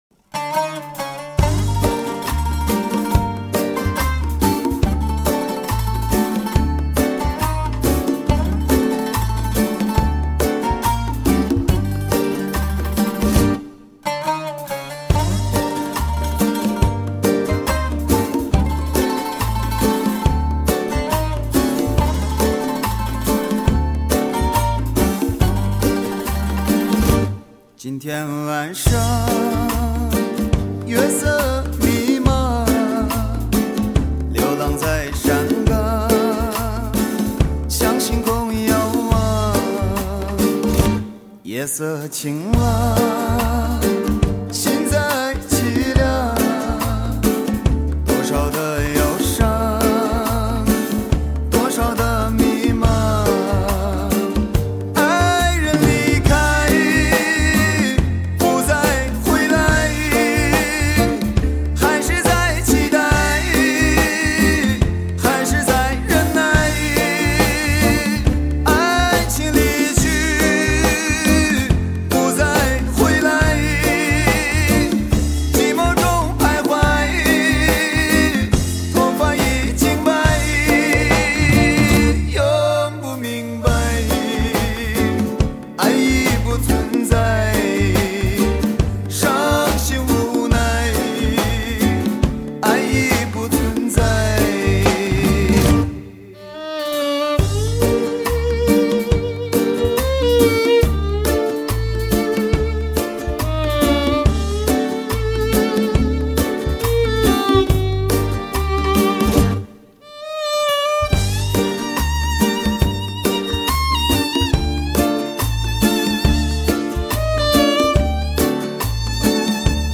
音乐风格以，“弗拉门戈”为主线，同时融入，拉丁、乡村、爵士等
迥然不同于人们传统概念中的新疆音乐，具有鲜明的世界音乐特质。